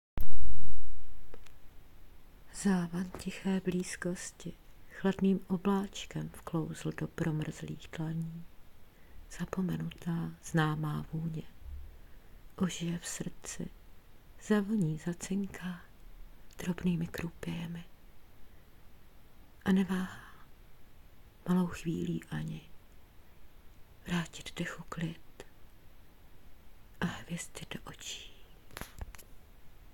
Přednes úžasný.
.....Velmi klidná stopa......